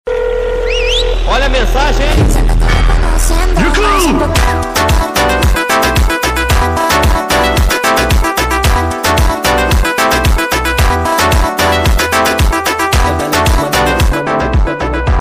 wait they fash Meme Sound Effect